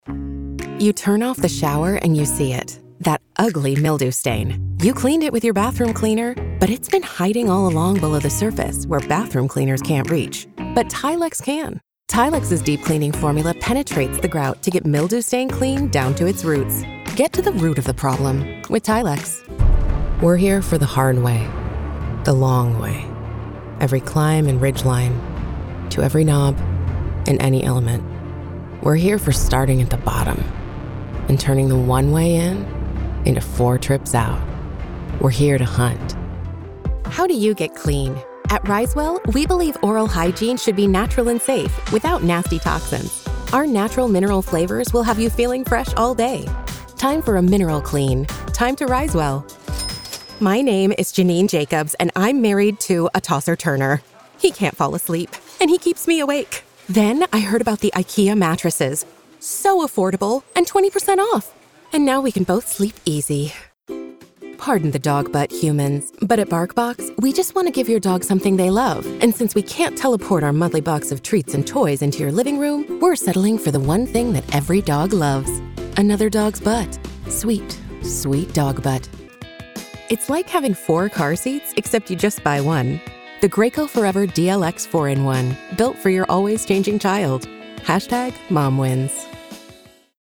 Commercial Demo
Voice Age
Young Adult
Middle Aged